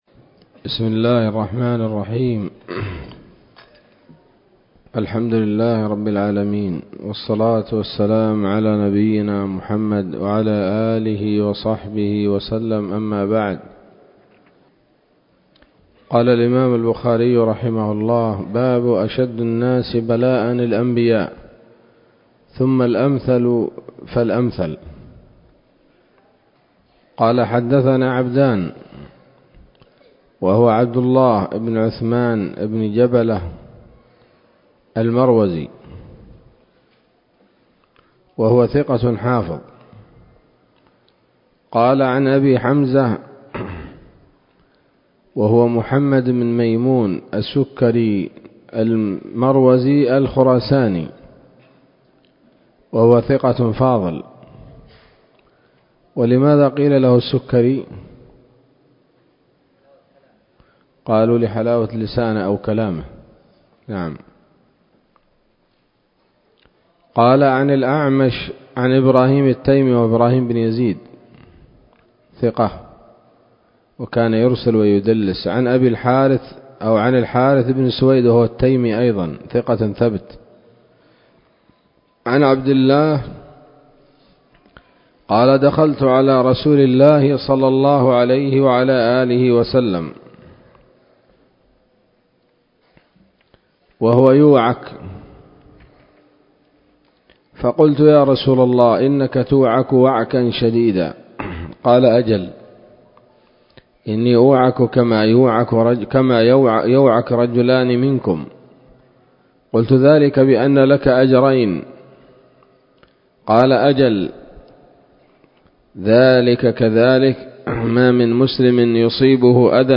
الدروس العلمية شروح الحديث صحيح الإمام البخاري كتاب المرضى من صحيح البخاري